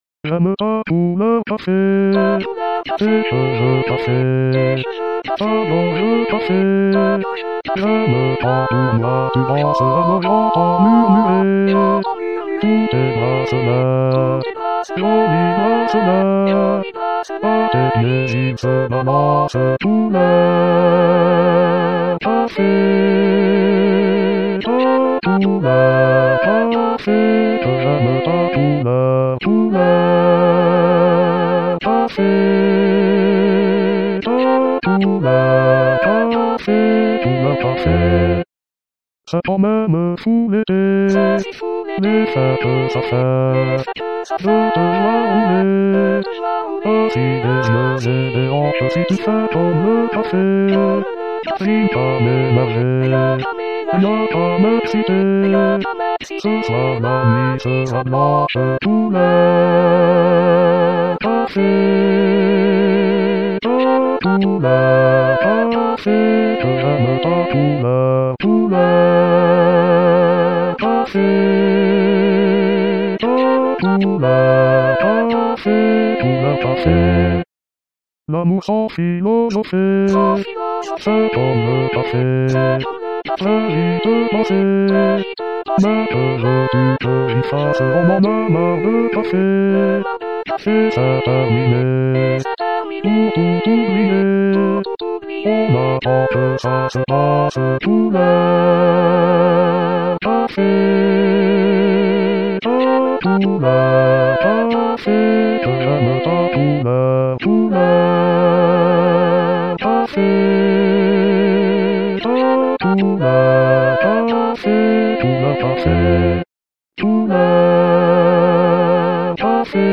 Les abréviations restent les mêmes: A = Altis, B= Basses, C = Choeur, F= Femmes, H= Hommes, S = Sopranes et T = Ténors; le signe + indique les fichiers où les autres voix sont en accompagnement.
81_couleur_cafe_h_voix.mp3